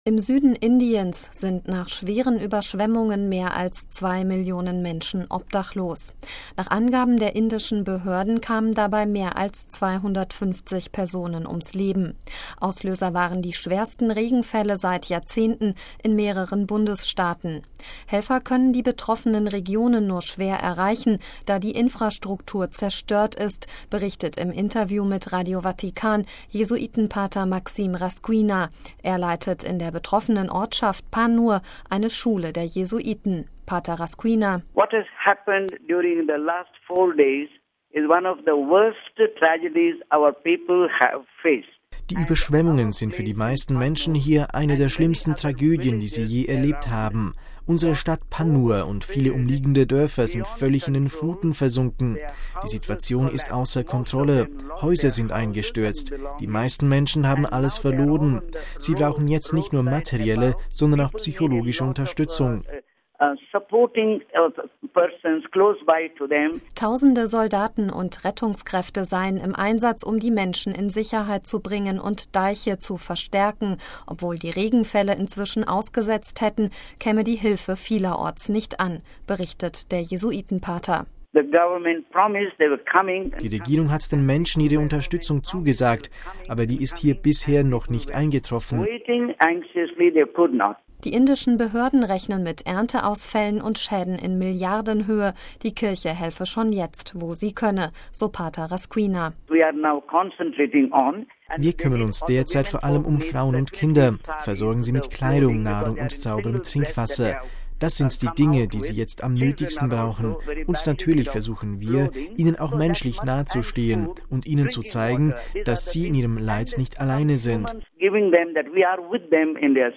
Obwohl die Regenfälle inzwischen ausgesetzt hätten, käme die Hilfe vielerorts nicht an, berichtet der Jesuitenpater: